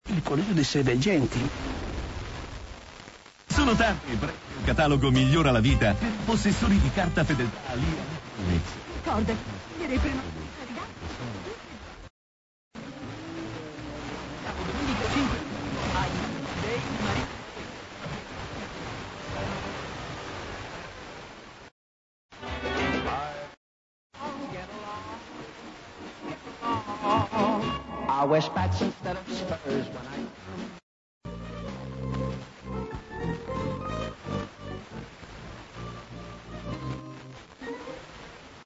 Meteor Scatter